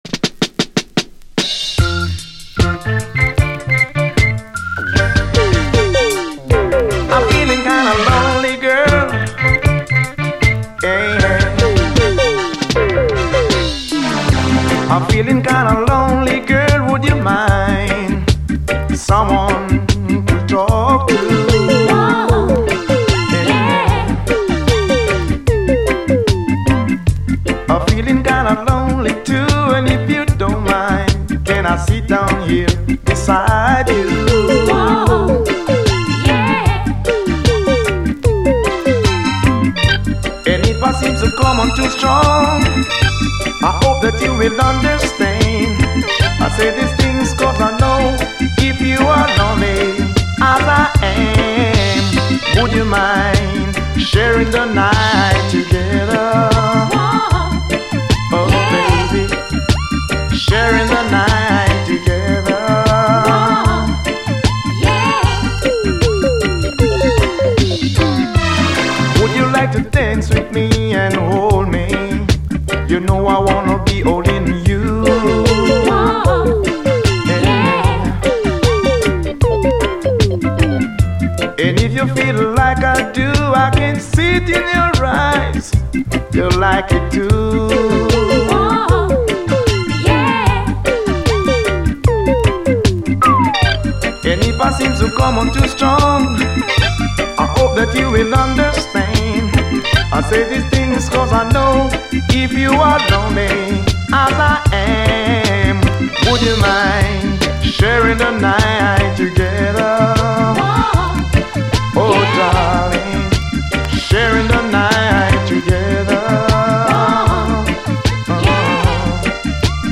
REGGAE
アナログ・シンセがイナタくもカラフルで沁みる。
後半はダブ。